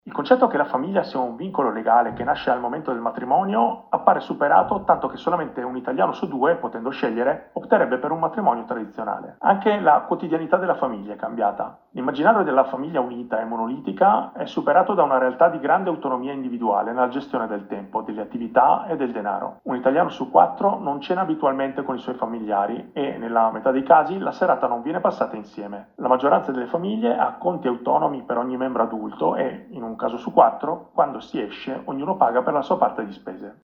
Per il 2024 serve creare un Paese con maggiore coesione e partecipazione. L’editoriale di fine anno